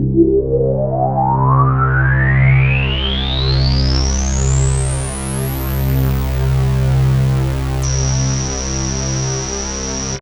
Long Reso Bass.wav